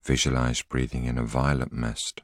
a voice sample is a single voiceover statement which can be used to compose voice collections.